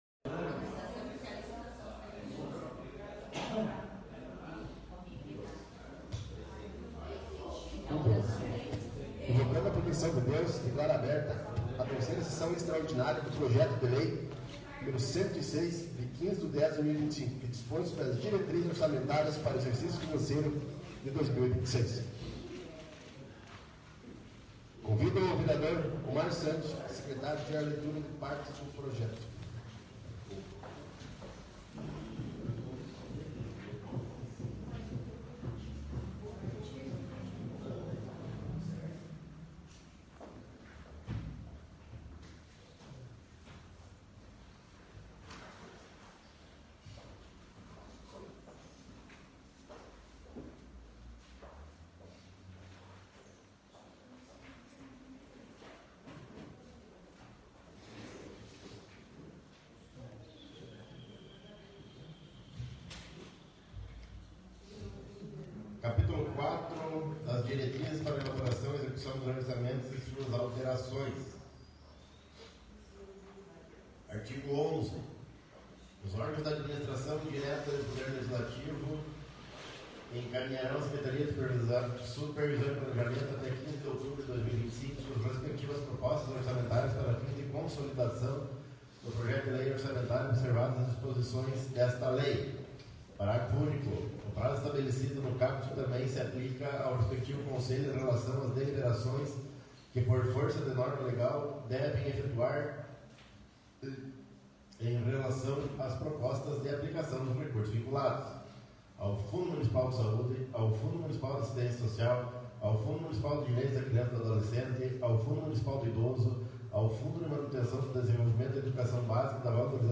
9ª Extraordinária da 1ª Sessão Legislativa da 16ª Legislatura